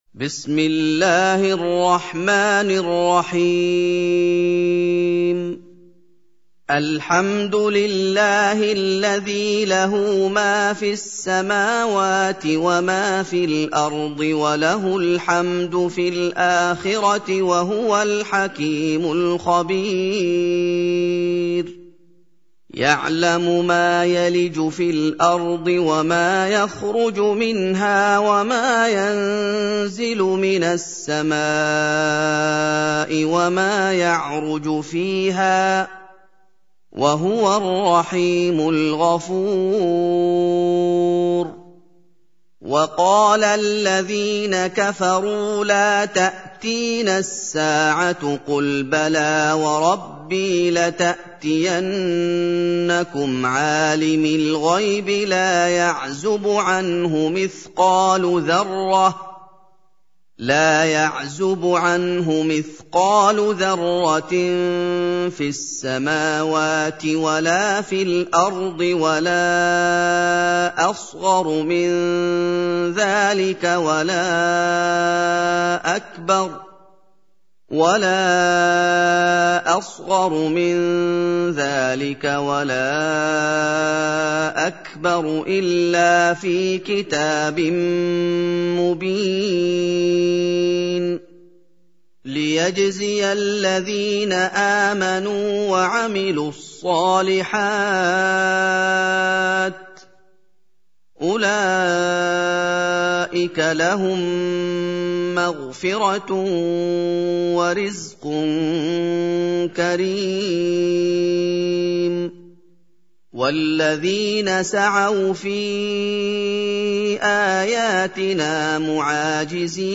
سورة سبأ | القارئ محمد أيوب